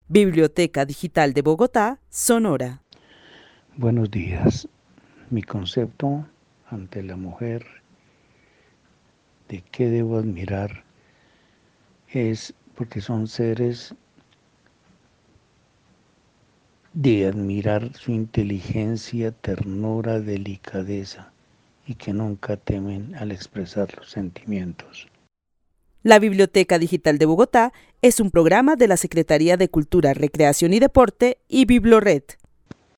Narración oral de un hombre que vive en la ciudad de Bogotá, y quien admira de las mujeres la suavidad, ternura, inteligencia y el que nunca temen expresas sus sentimientos. El testimonio fue recolectado en el marco del laboratorio de co-creación "Postales sonoras: mujeres escuchando mujeres" de la línea Cultura Digital e Innovación de la Red Distrital de Bibliotecas Públicas de Bogotá - BibloRed.